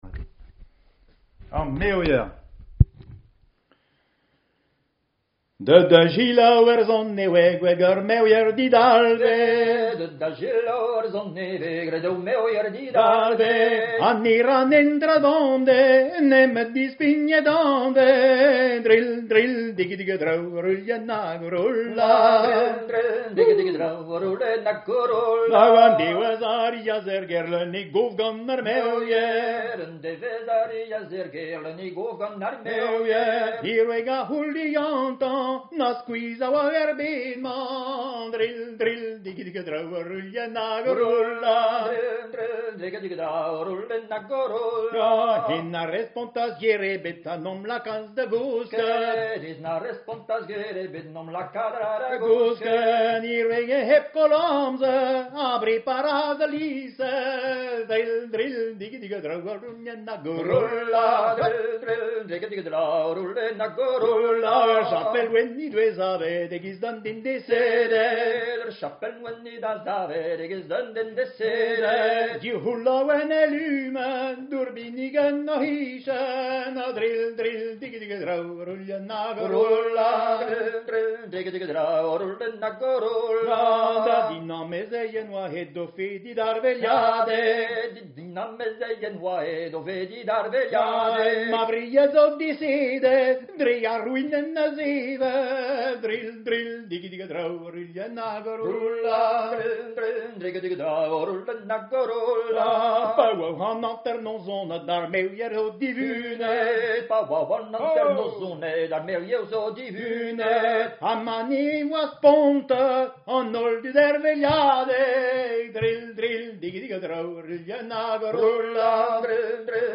Chanté en marche (modèle du Kant a Gan) ou bien en Jabadao de la suite Treger
Jabadao